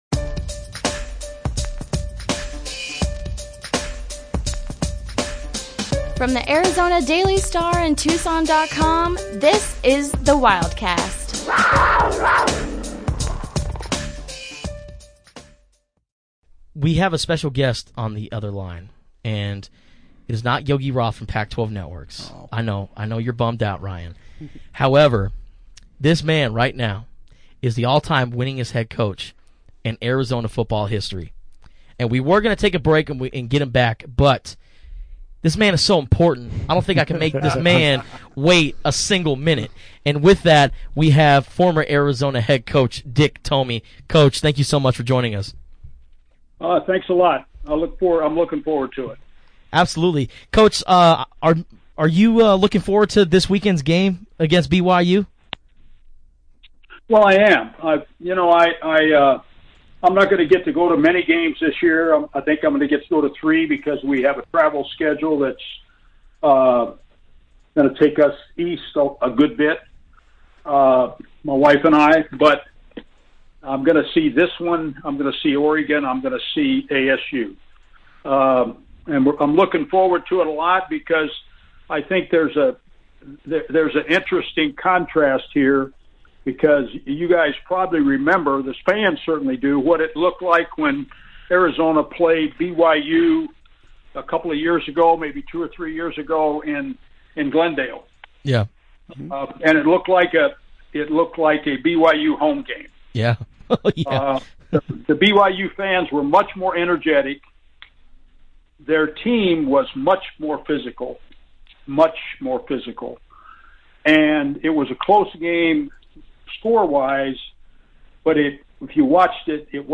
The Wildcast, Episode 122 (BONUS): Exclusive interview with Dick Tomey